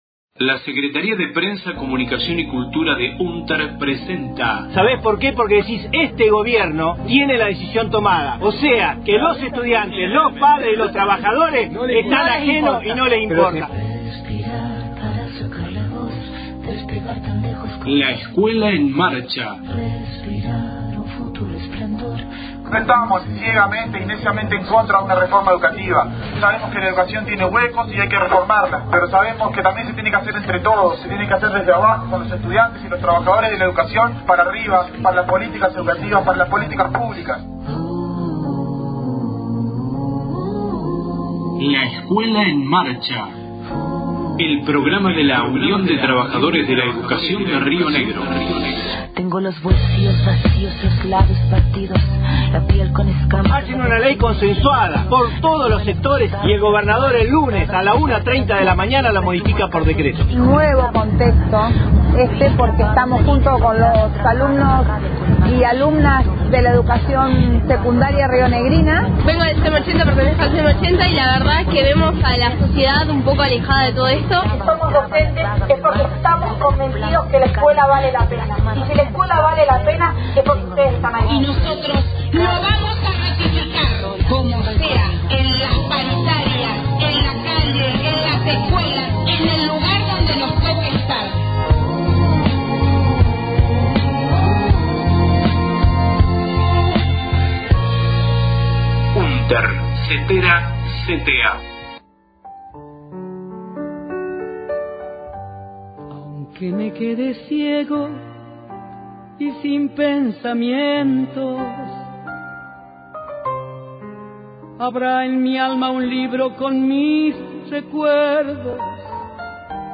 • Voces de acto y movilización del 24 de marzo, por memoria, verdad y justicia en Roca – Fiske Menuco.